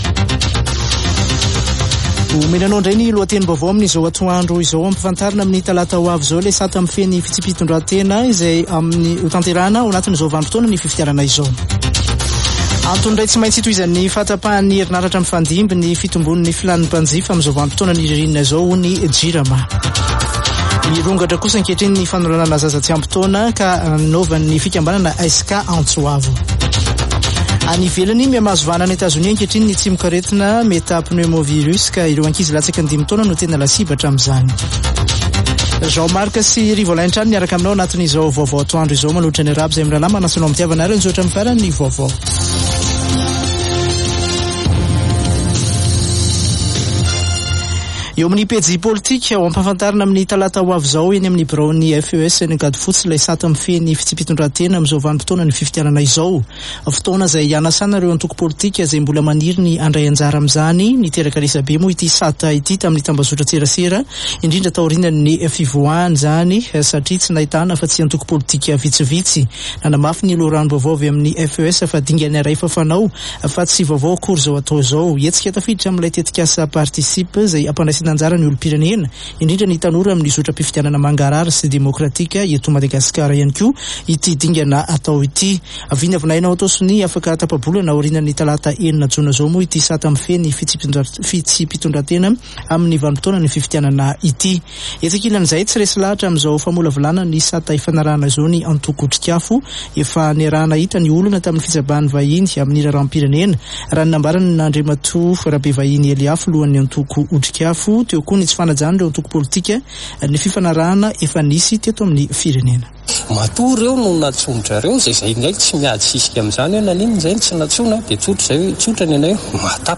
[Vaovao antoandro] Zoma 2 jona 2023